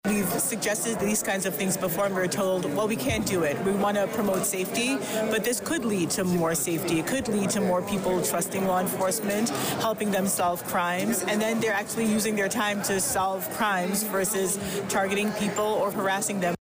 during Thursday’s meeting at Laura Lee Fellowship House